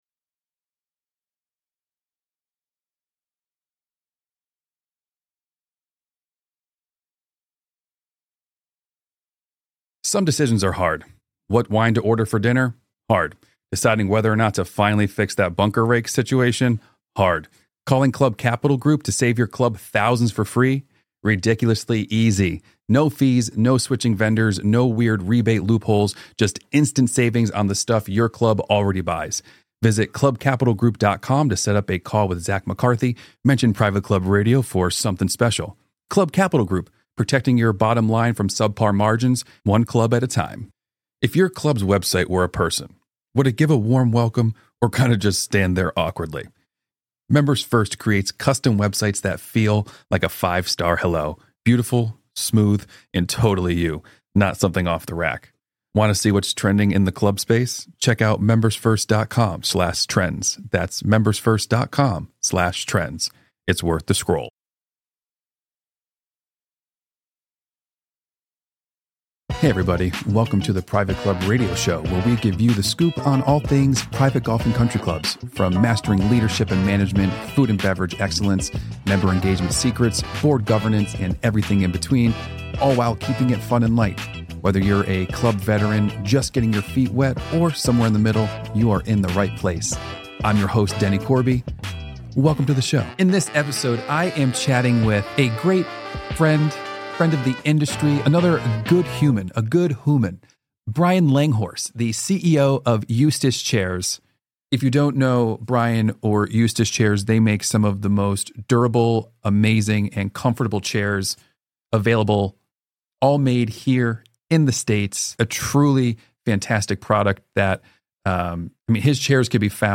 One of the most insightful moments in the conversation is the discussion around how clubs can select furniture that aligns with how the space is actually used.